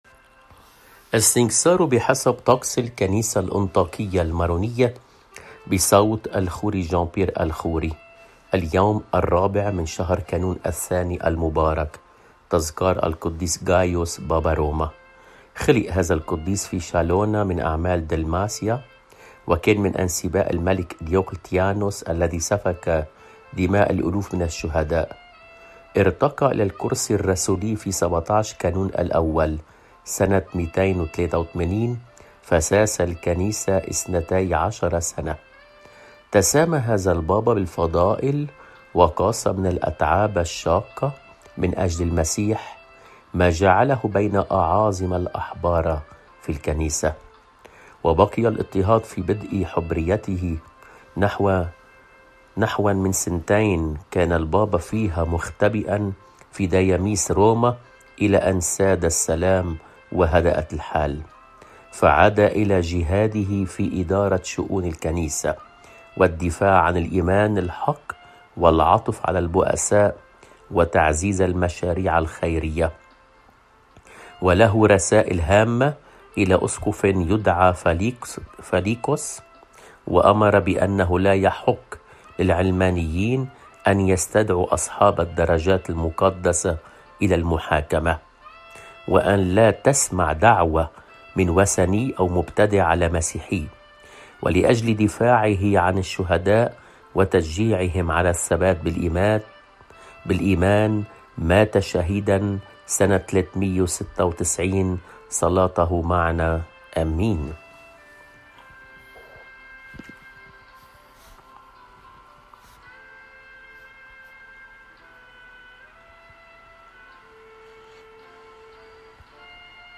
قدّيس يوم ٤ كانون الثانيMP3 • 1150KB